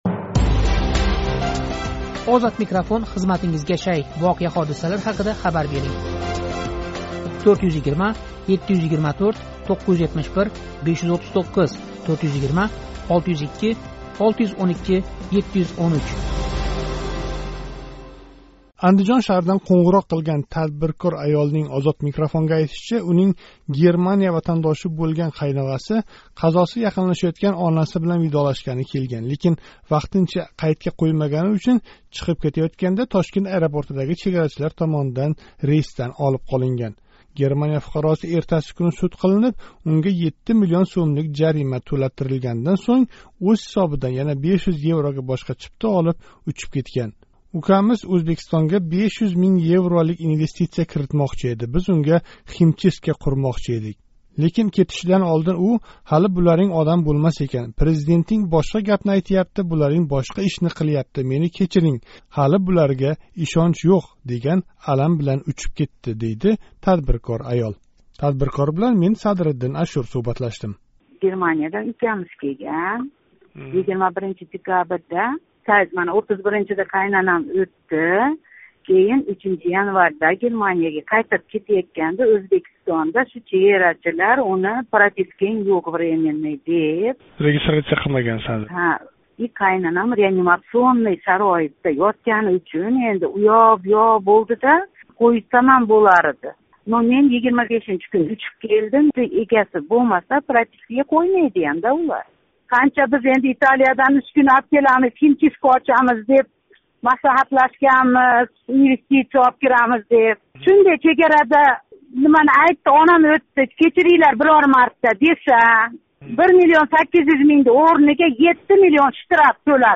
Андижон шаҳридан қўнғироқ қилган тадбиркор аёлнинг OzodMikrofonга айтишича, унинг Германия ватандоши бўлган қайноғаси қазоси яқинлашаётган онаси билан видолашгани келган, лекин, вақтинча қайдга қўймагани учун чиқиб кетаётганда Тошкент аэропортидаги чегарачилар рейсдан олиб қолган.